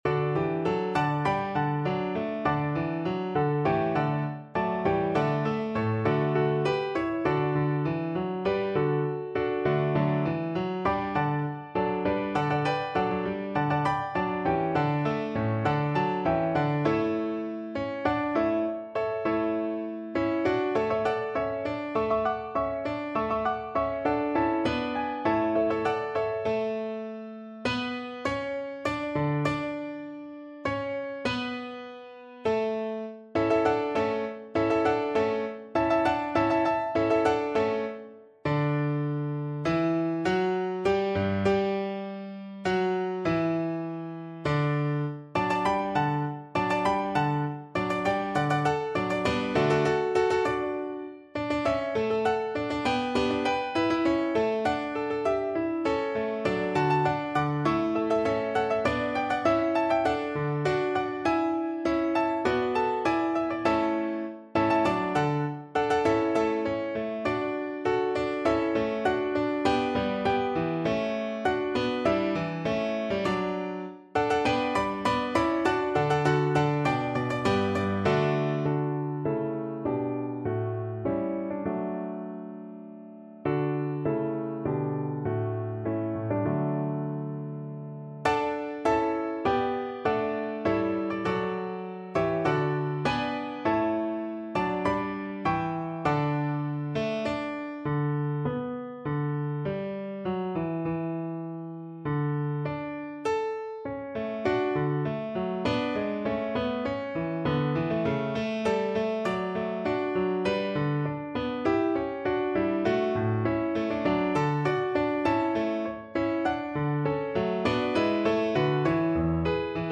Free Sheet music for Soprano (Descant) Recorder
4/4 (View more 4/4 Music)
Allegro (View more music marked Allegro)
Classical (View more Classical Recorder Music)